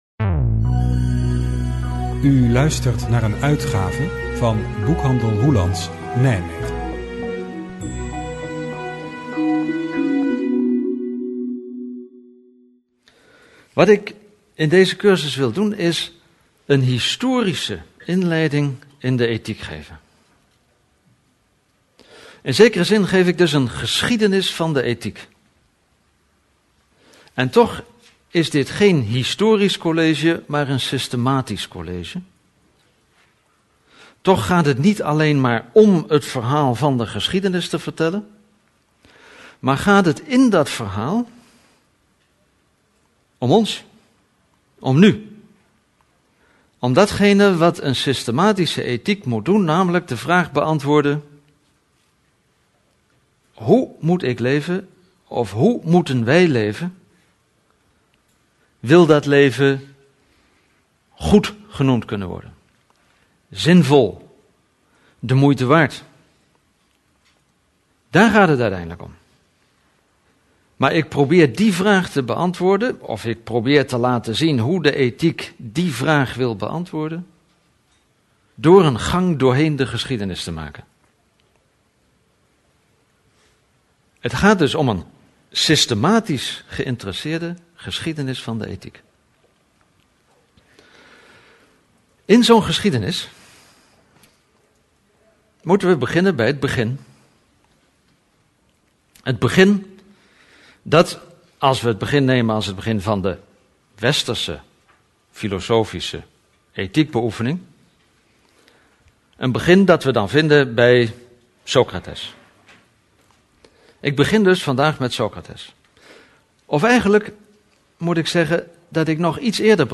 Luisterboek
De opnames zijn gemaakt tijdens een echte cursus, maar ze geven niet die gehele cursus weer. Elk college dat in werkelijkheid anderhalf uur duurt, is hier tot een uur gecomprimeerd.